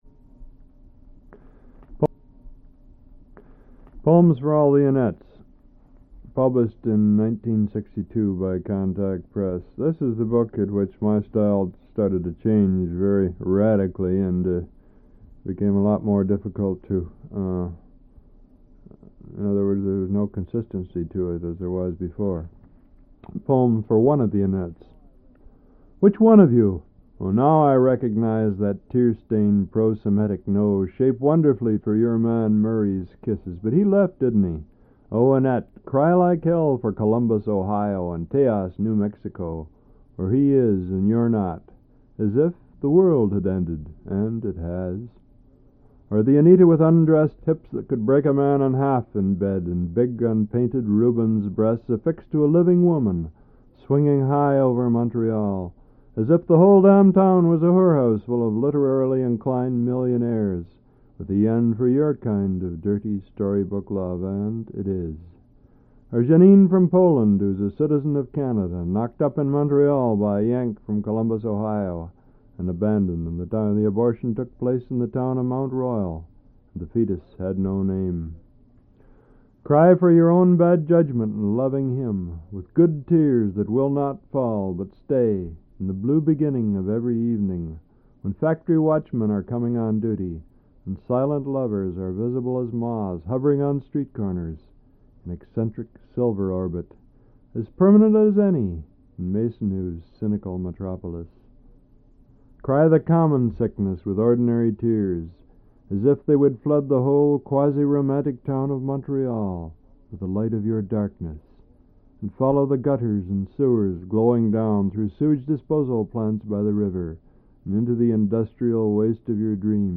Al Purdy Reads his poetry
1/4"' Reel-to-Reel Tape